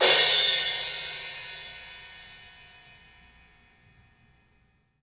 Index of /90_sSampleCDs/E-MU Producer Series Vol. 5 – 3-D Audio Collection/3DPercussives/3DPACymbals